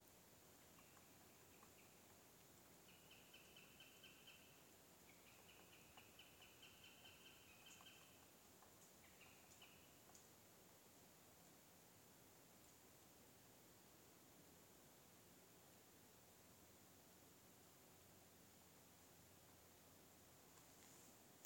Birds -> Birds of prey ->
Northern Goshawk, Accipiter gentilis
StatusVoice, calls heard
Pašai liekas, ka vistu, jo zvirbuļvanagam ir straujāks temps.Tūlīt pēc tam sīļi sataisīja pamatīgu traci.